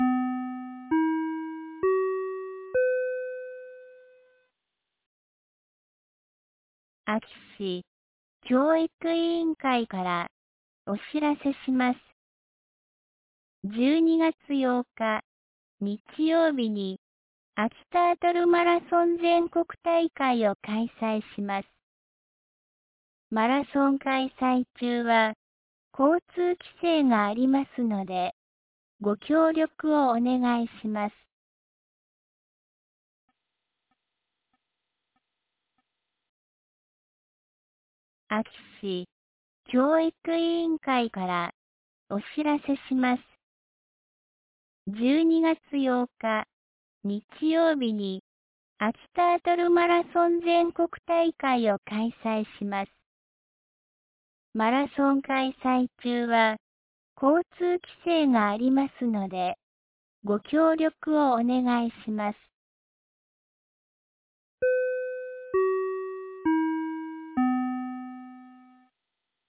2024年12月06日 16時31分に、安芸市より全地区へ放送がありました。